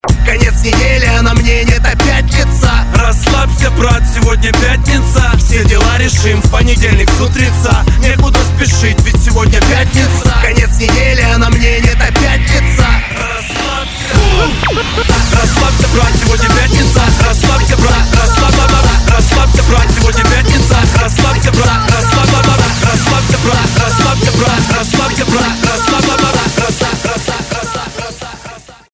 Рэп, Хип-Хоп, R'n'B